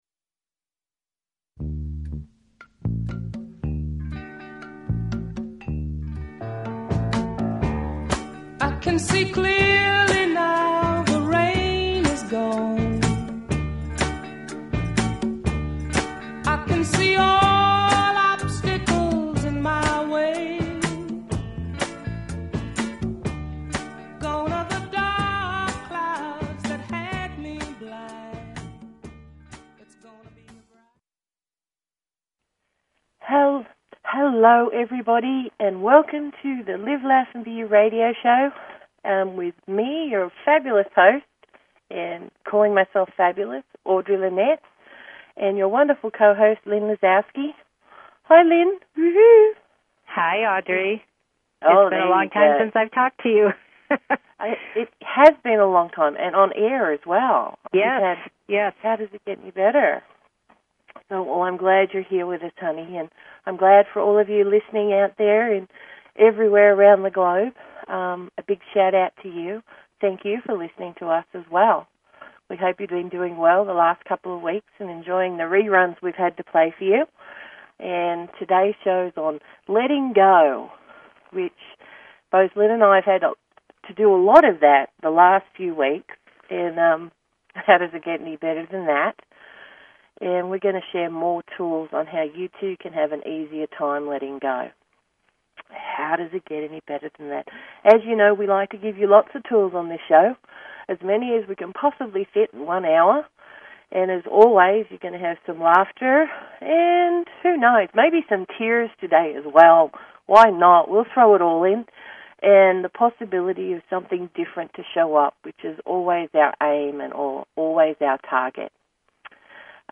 Talk Show Episode, Audio Podcast, Live_Laugh_and_BE_You and Courtesy of BBS Radio on , show guests , about , categorized as